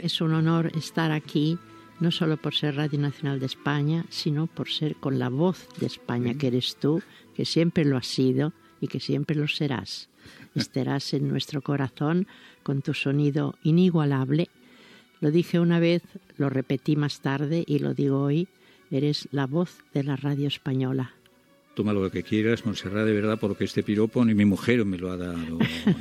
Fragment d'una entrevista a la soprano Montserrat Caballé.
Entreteniment